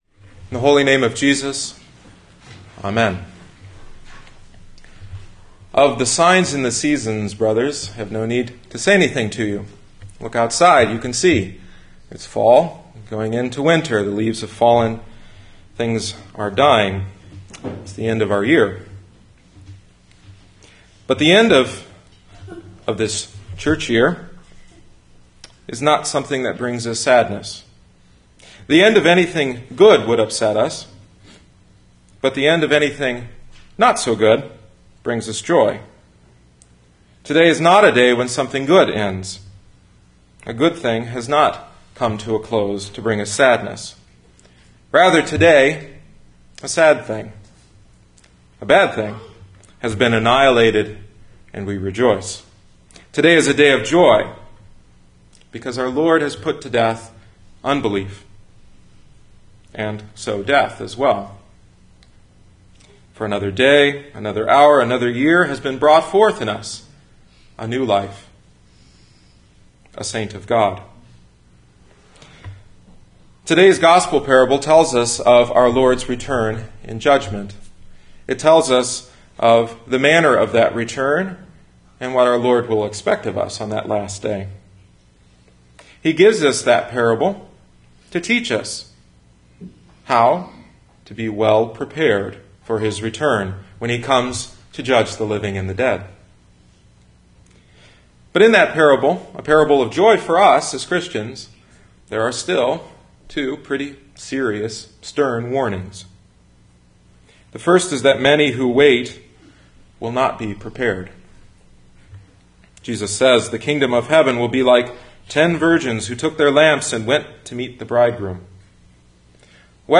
Grace Lutheran Church – Dyer, Indiana